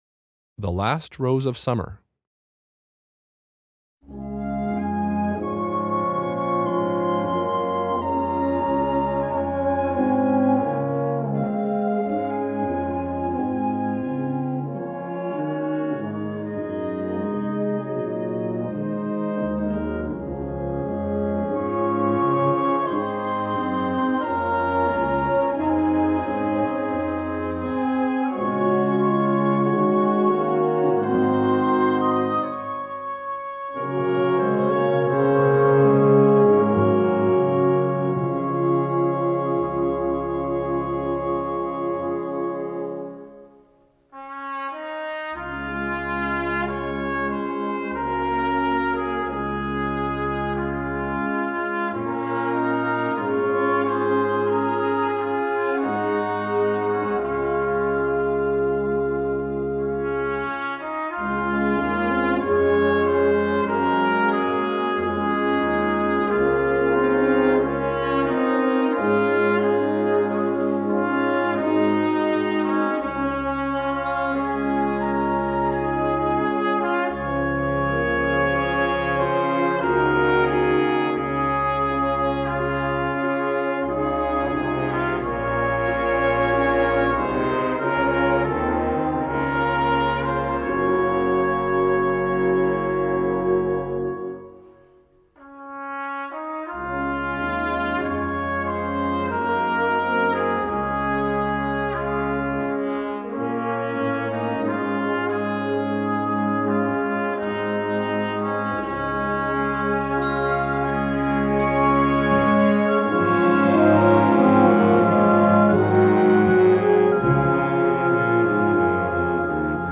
Concert Band
Traditional
Irish folk melody